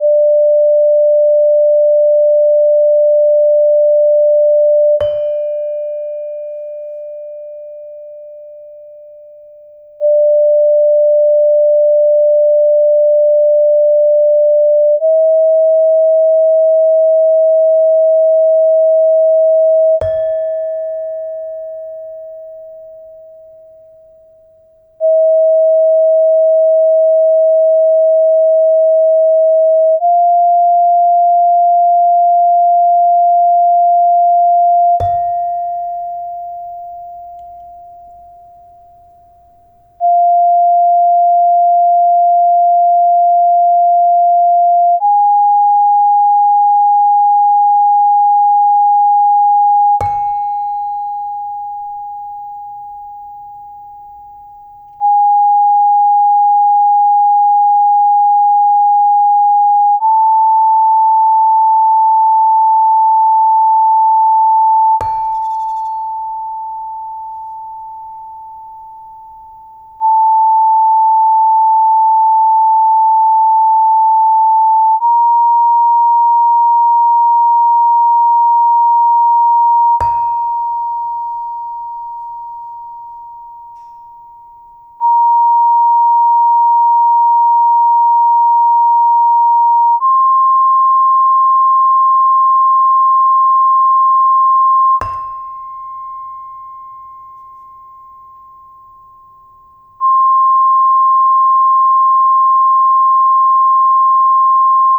5-second Saron Barung Pelog Tones of Gamelan Kyai Parijata Compared with Sine Tones of the Same Fundamental Frequency.wav (8.83 MB)